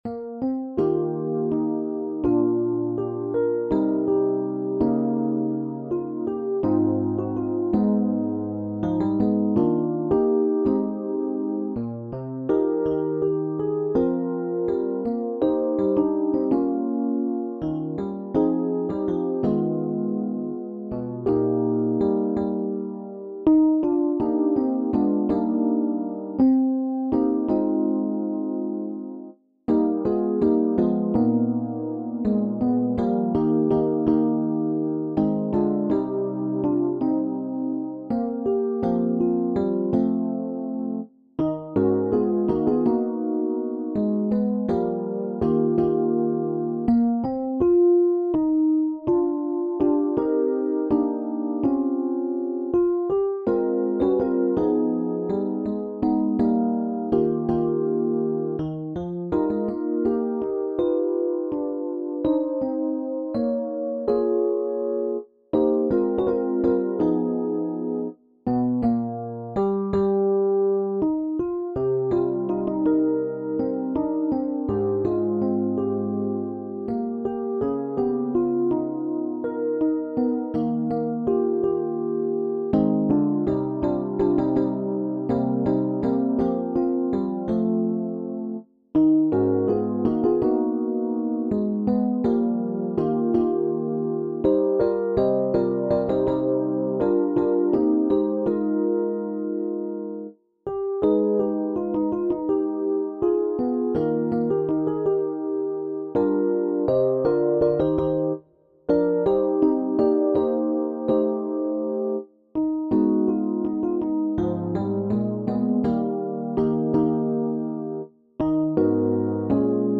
SATB div. | SSAB div.